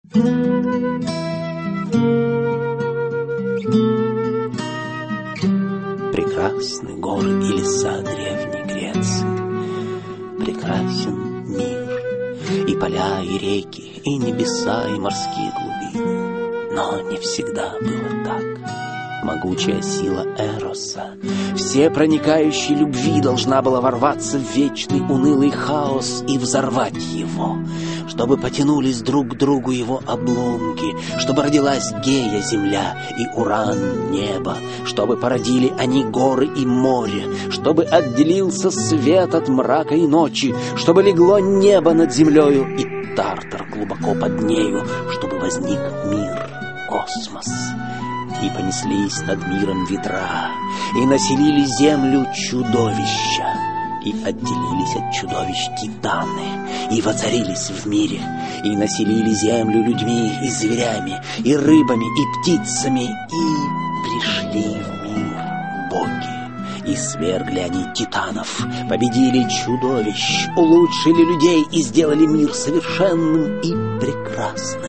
Аудиокнига Легенды и мифы Древней Греции. Боги и герои. Аудиоспектакль | Библиотека аудиокниг